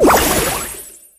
surge_atk_01.ogg